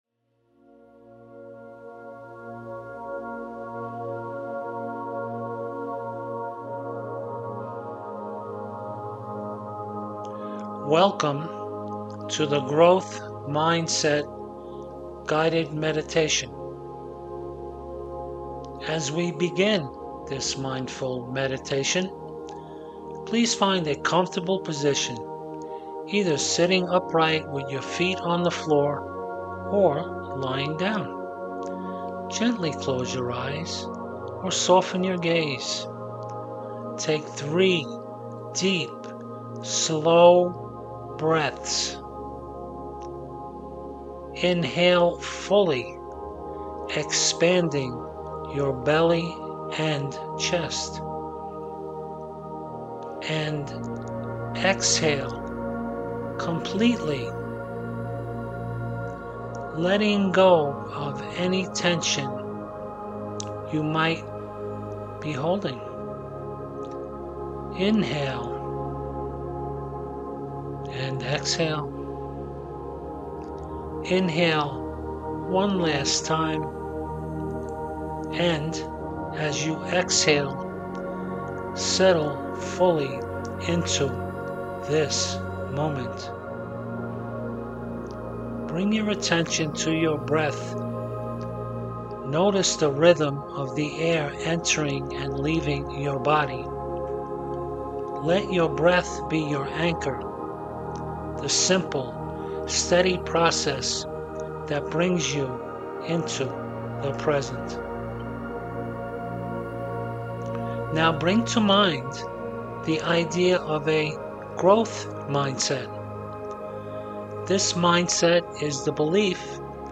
Growth-Mindset-Guided-Meditation-1.mp3